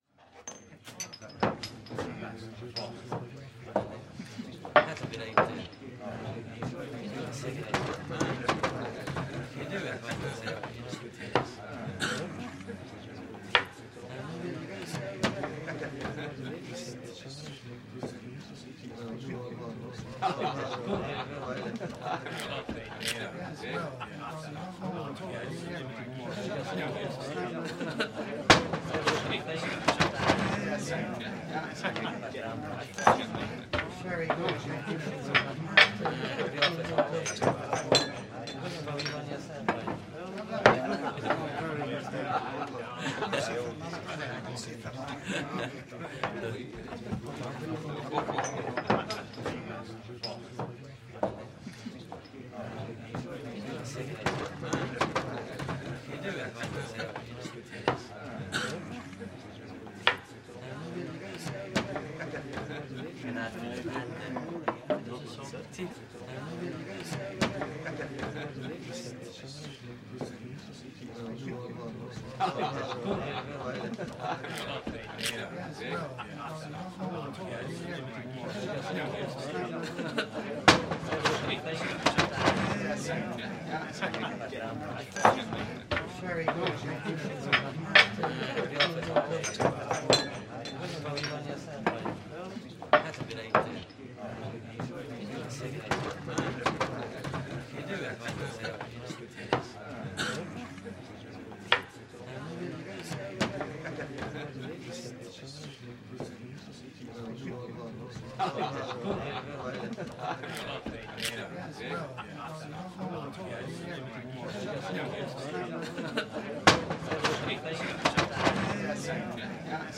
Здесь вы найдете фоновые шумы разговоров, звон бокалов, смех гостей и другие характерные звуки заведения.
Шум и гам в тесном пабе